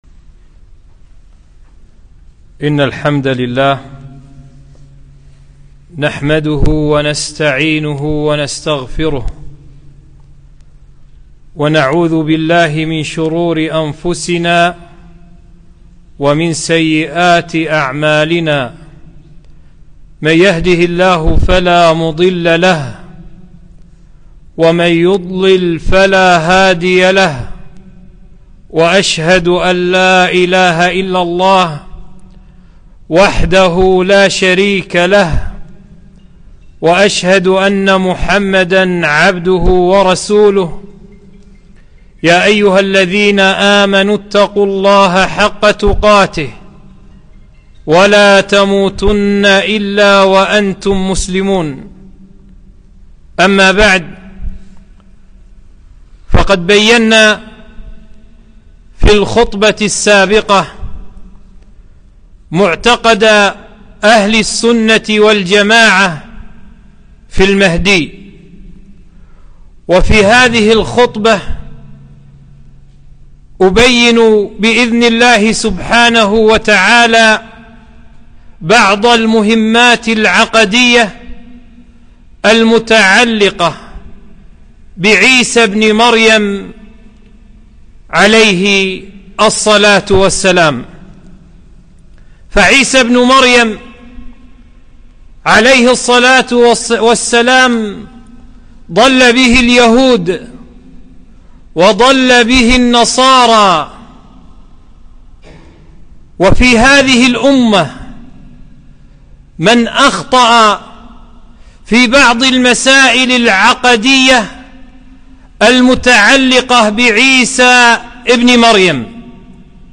خطبة - مسائل عَقَدية تتعلق بعيسى ابن مريم عليه الصلاة والسلام